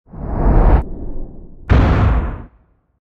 explode.ogg.mp3